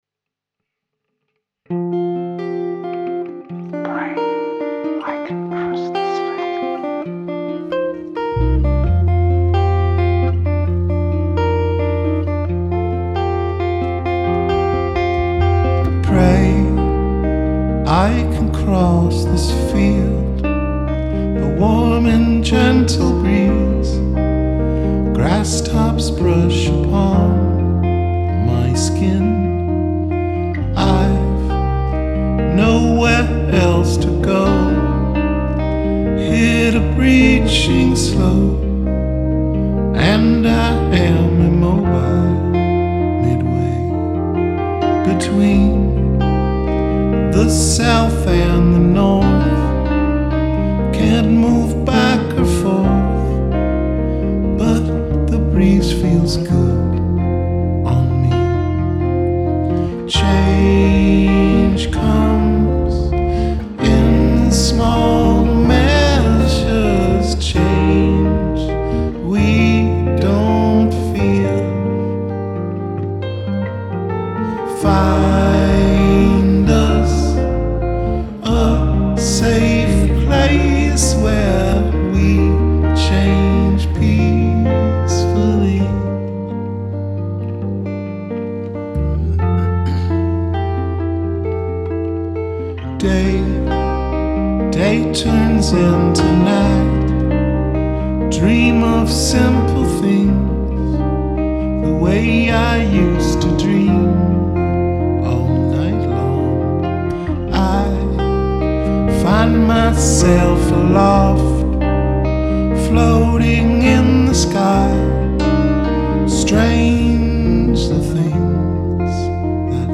Rehearsals 17.3.2012